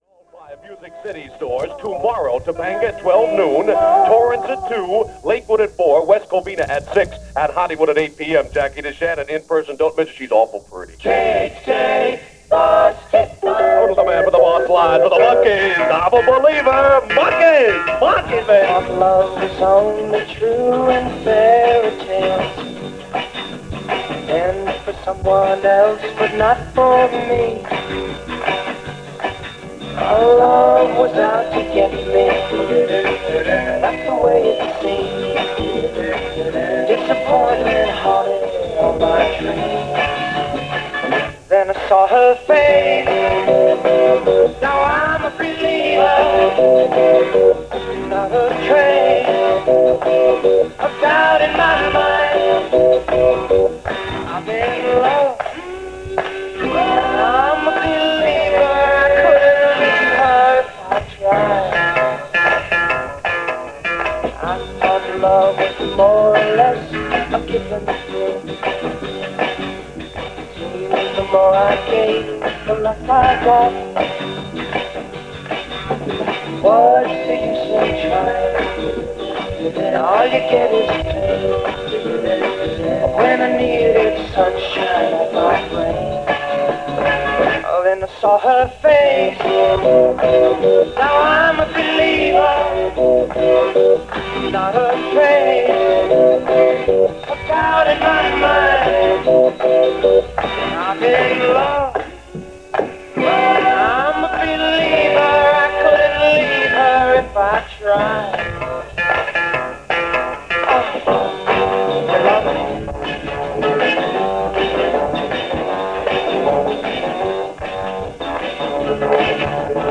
By the end of this 35 minute snapshot from November 1966, there is a tiny portion of the tape devoted to a show from KCRW – the Santa Monica College radio station.
Radio-November-1966.mp3